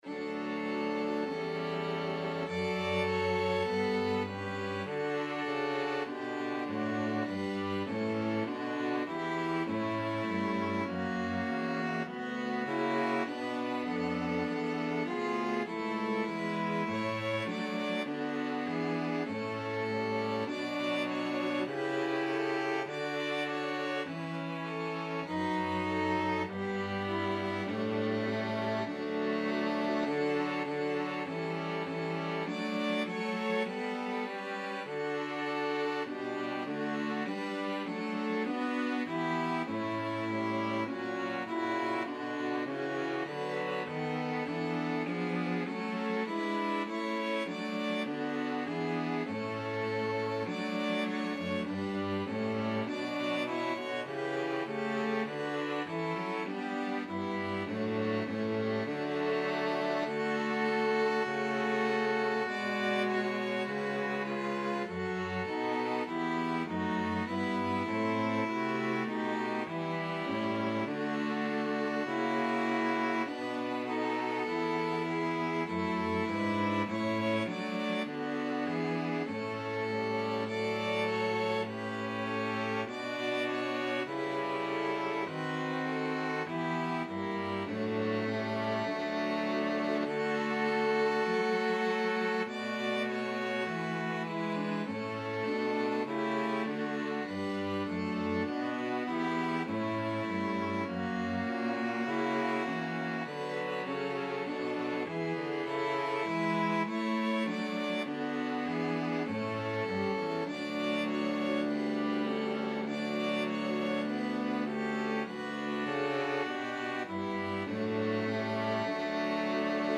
Violin 1Violin 2ViolaCello
4/4 (View more 4/4 Music)
Classical (View more Classical String Quartet Music)